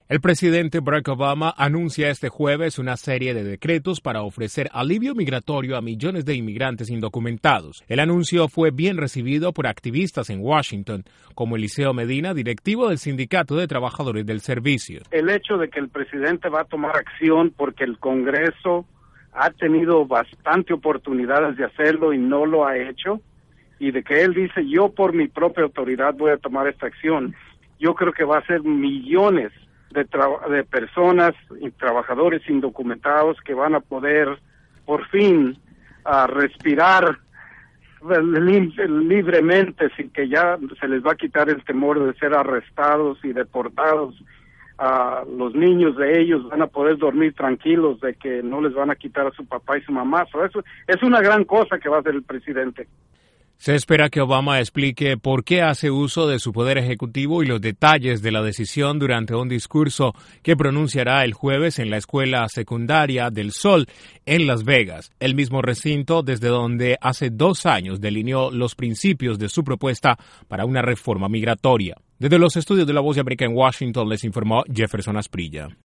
El presidente Barack Obama emitirá sus decretos en materia de Inmigración. El hecho impactará el futuro de más de 11 millones de indocumentados y los activistas acogieron con beneplácito el anuncio. Desde la Voz de América en Washington informa